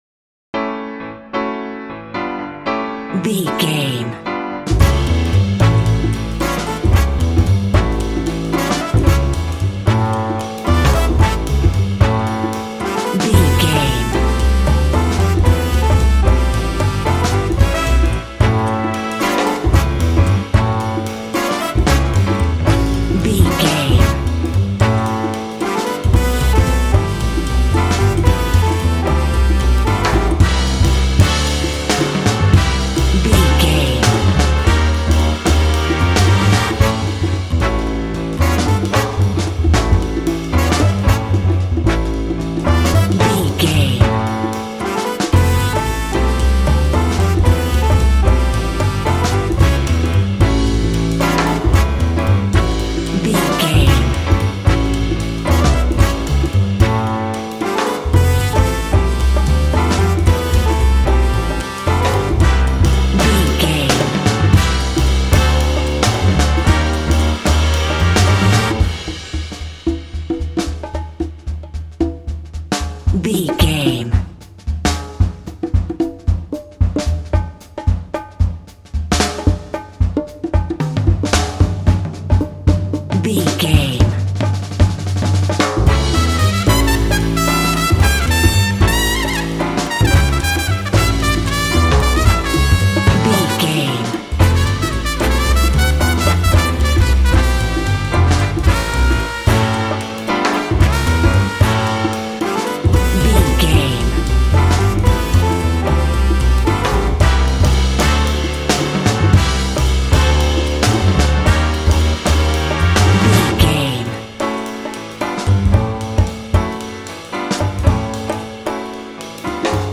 Uplifting
Mixolydian
joyful
energetic
drums
conga
double bass
brass
piano
big band